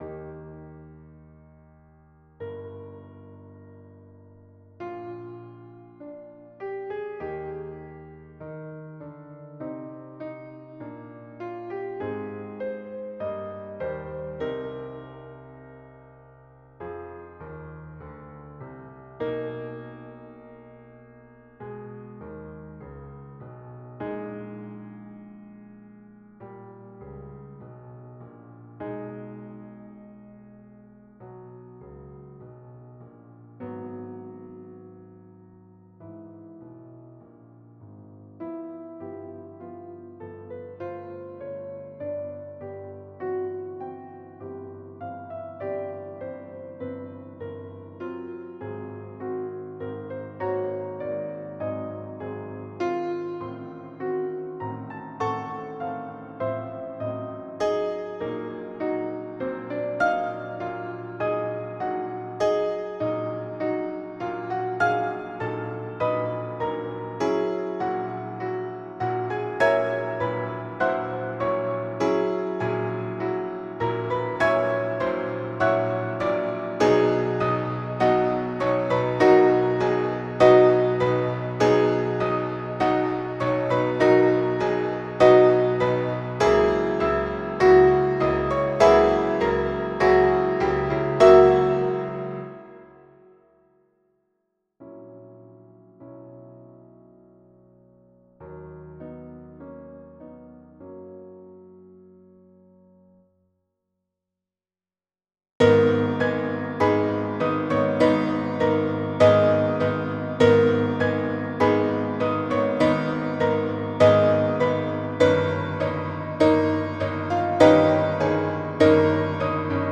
Symphony No. 3 - Adagio (1877): Arranged for FOUR pianos
This is a midi arrangement of the 1877 version of the adagio of the third symphony for four pianos (eight staffs). Each piano plays a particular section of the orchestra. The piano on the left side plays the violin parts, the next piano plays the woodwind parts, the next plays the brass parts and the piano on the right side plays the parts of the violas, cellos and basses.
Symphony-no.-3-Adagio-1877_-midi-arr.-for-four-pianos.flac